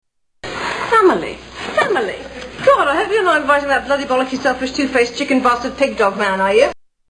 Tags: TV Series Absolutely Fabulous Comedy Absolutely Fabulous clips British